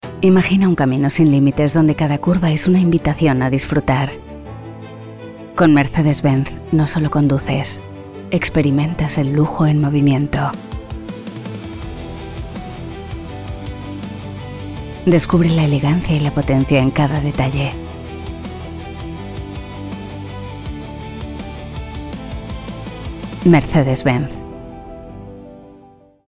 Versatile, Elegant, Sincere, Warm tones. 30-40.
Commercial, Confident, Strong, Cool, Moody